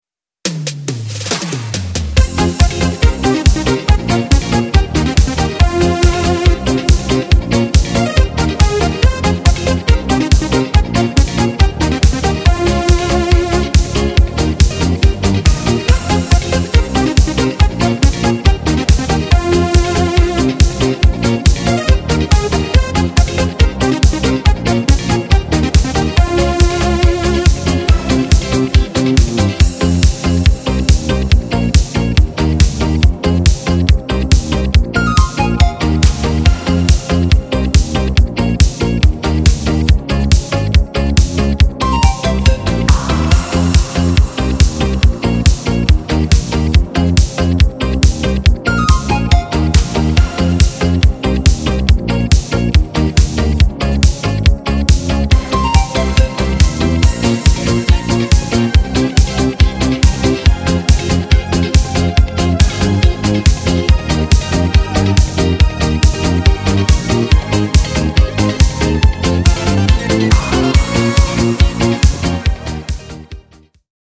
Wersja instrumentalna
Disco Polo